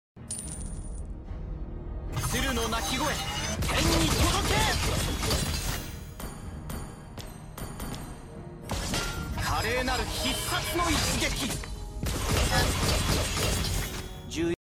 suara ultimate ling cc balik sound effects free download
Mp3 Sound Effect suara ultimate ling cc balik cuy